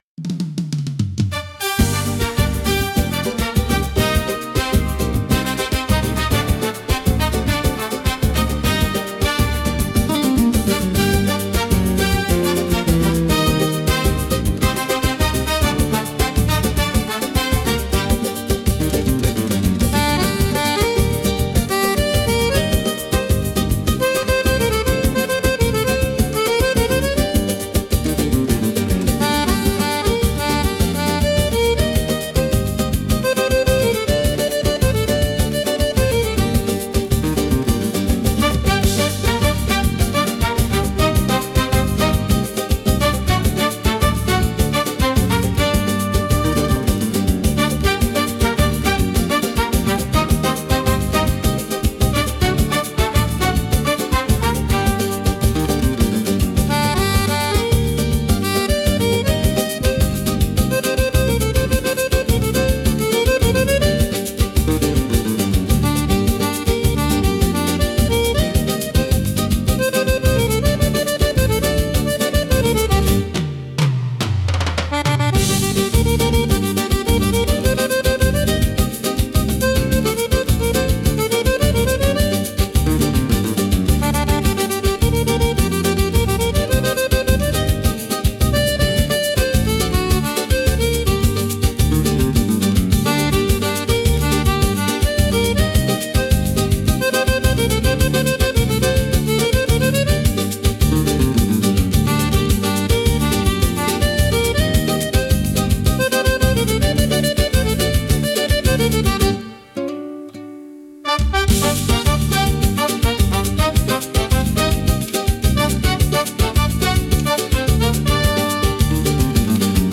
música e arranjo: IA) instrumental